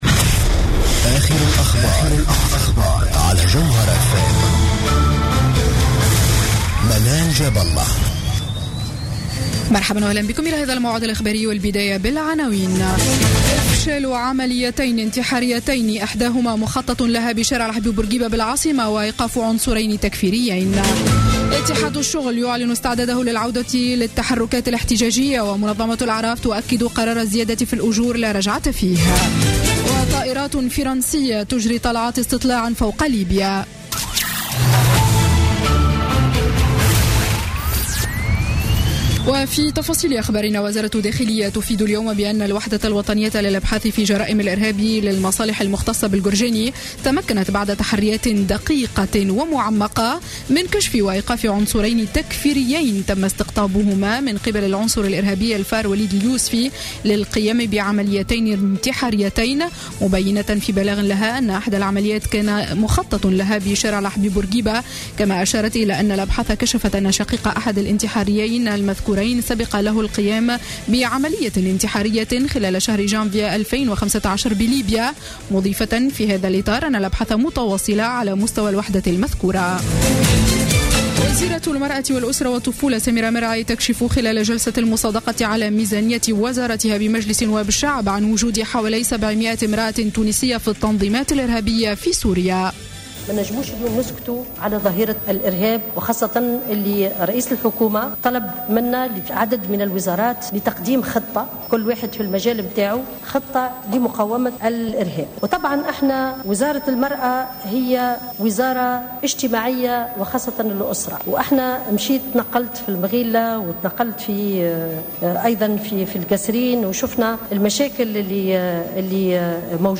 Journal Info 19:00 du Vendredi 04 Décembre 2015